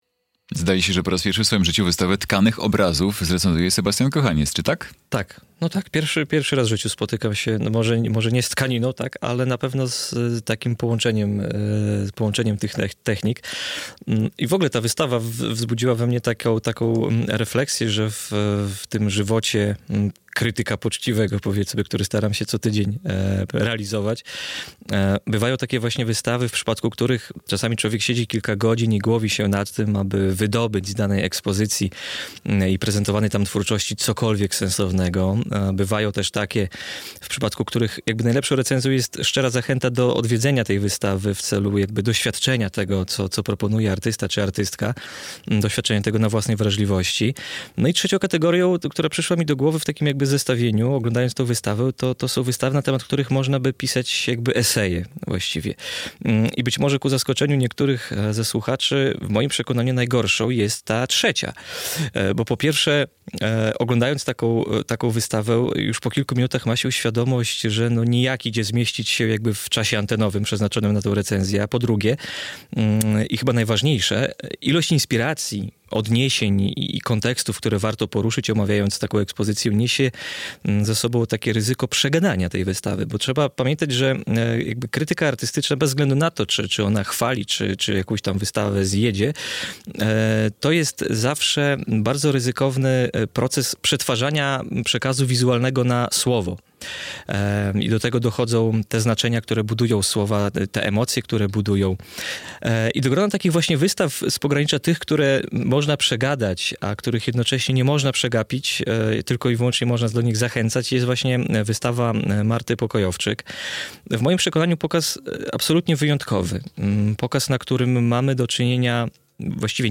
Recenzja